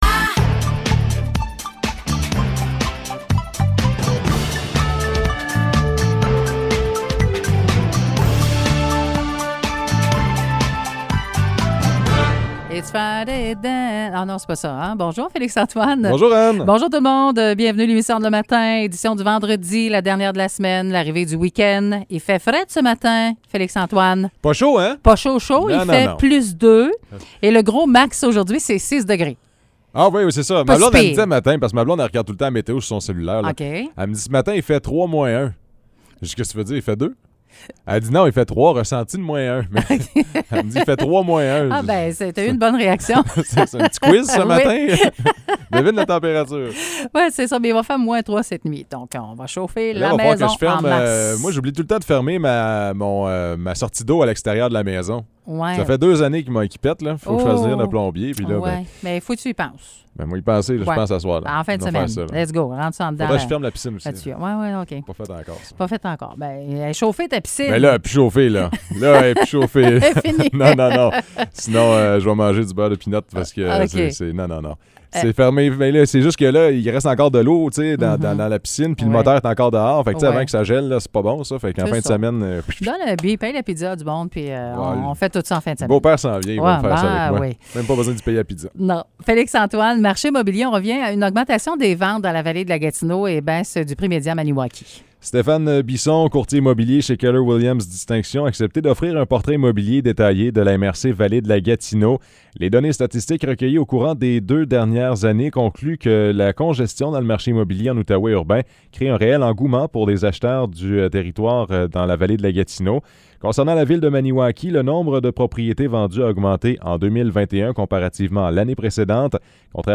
Nouvelles locales - 22 octobre 2021 - 9 h